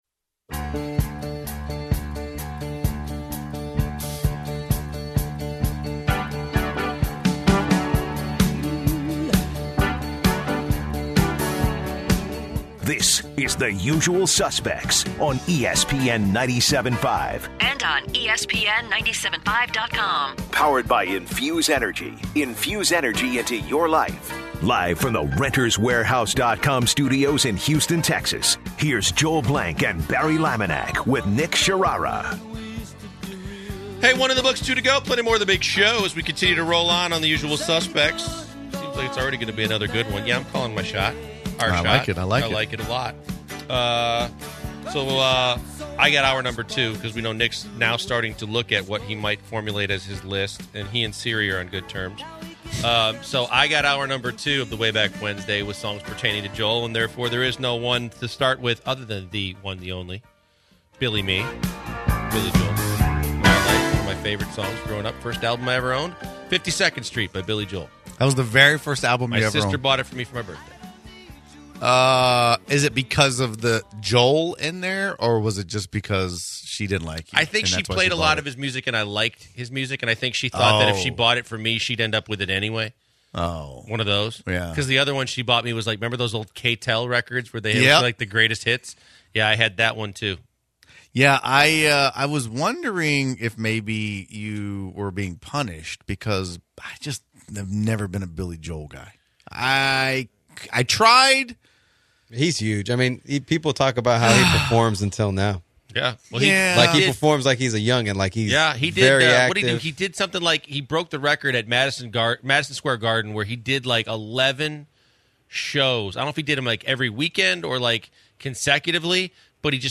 On the second hour the guys talk about Jake Elliott hitting a 61 yard field goal, they also talk about they also play sound bites from people who are sleep talking.